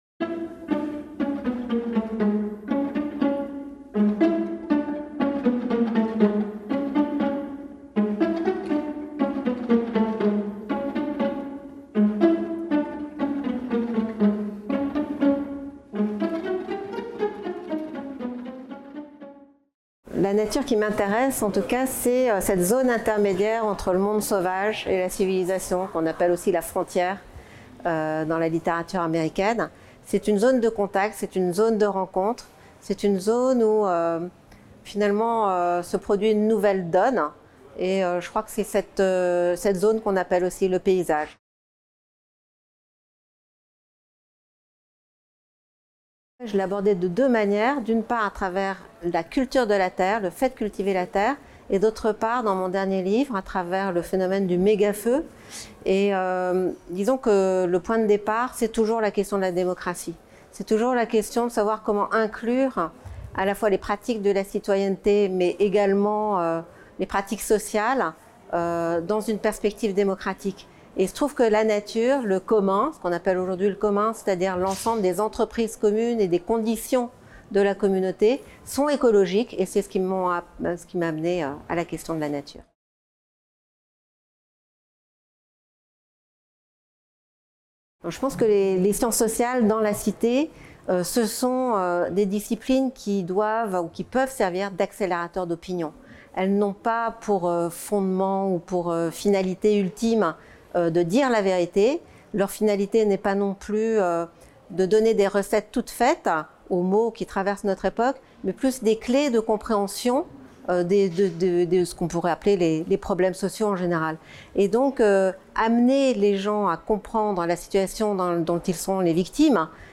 Favoris Ajouter à une playlist Entretien